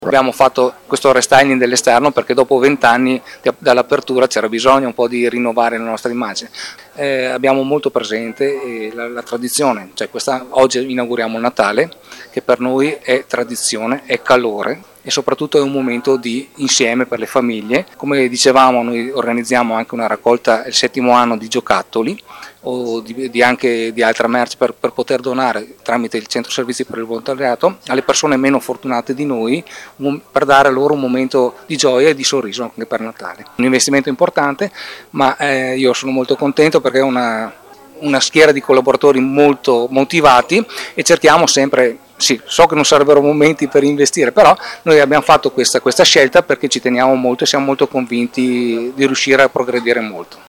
Al microfono del nostro corrispondente